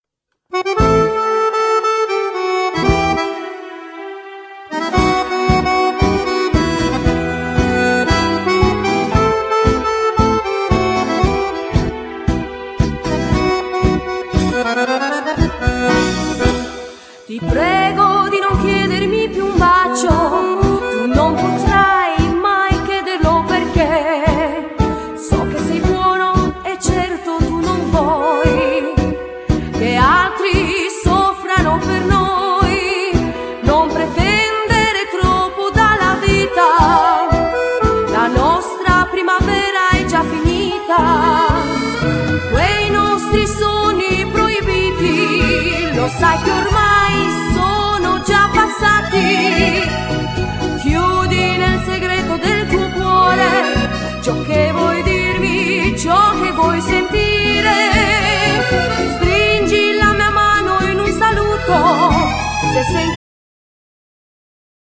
Genere : Liscio Folk